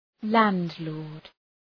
{‘lænd,lɔ:rd}